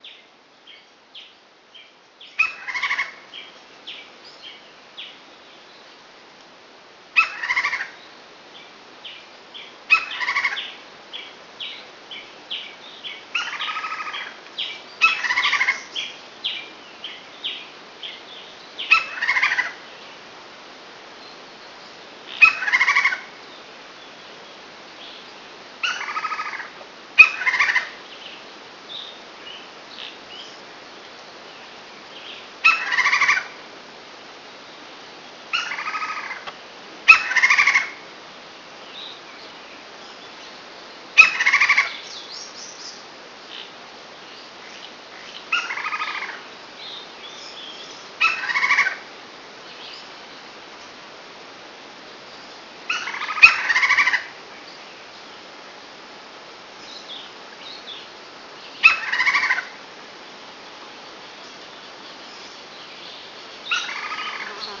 PICA-PAU-DE-BANDA-BRANCA
Nome em Inglês: Lineated Woodpecker